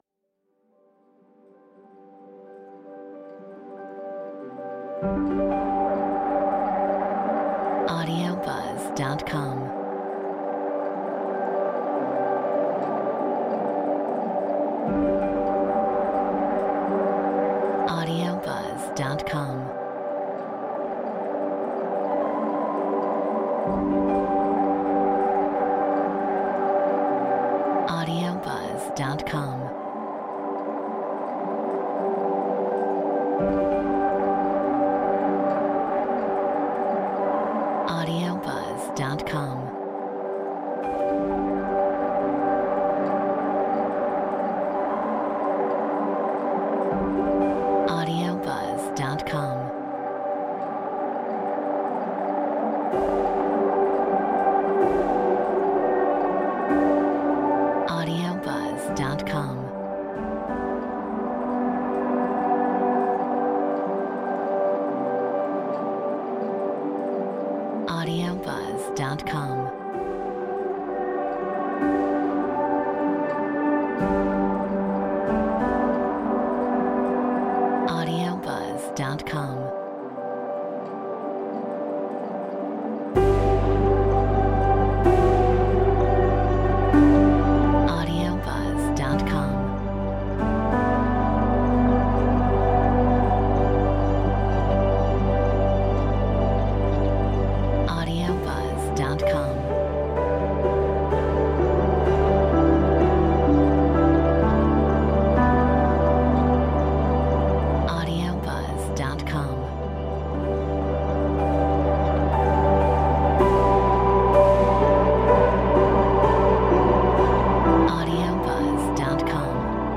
Metronome 127